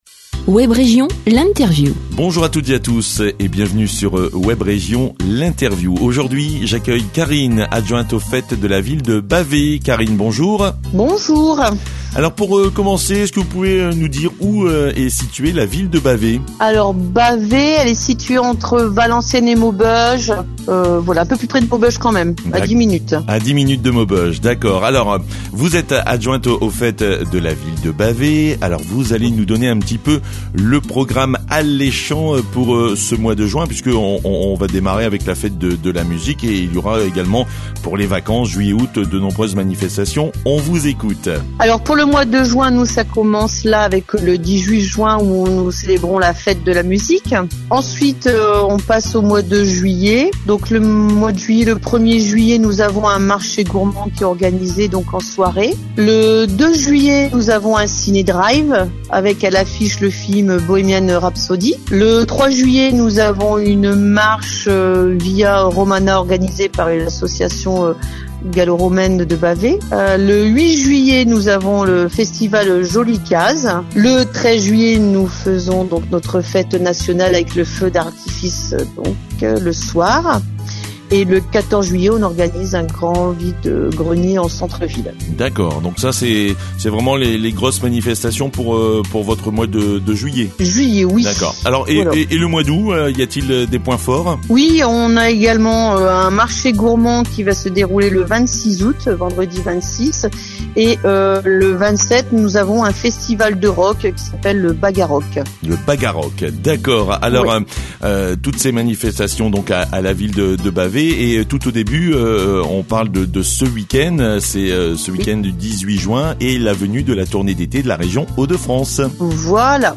ITW VILLE DE BAVAY
ITW-VILLE-DE-BAVAY.mp3